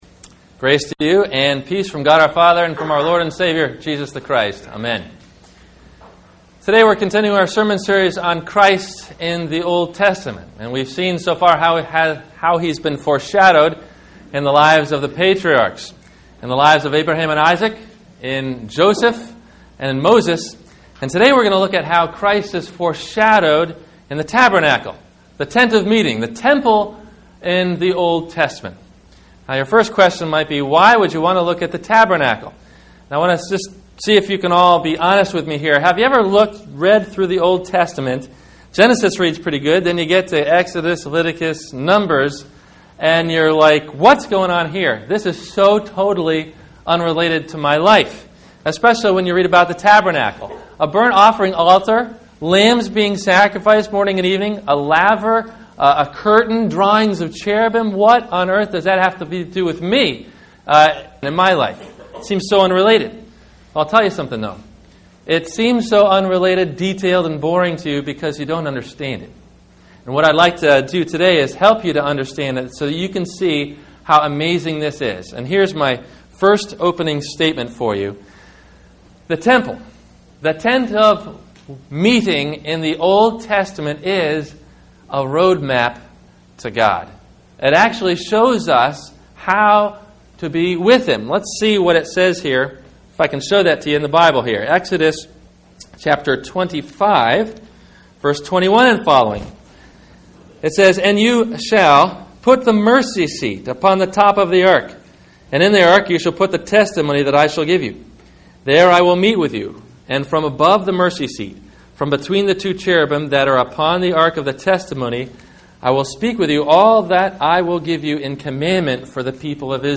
The Day of Atonement – Sermon – April 22 2012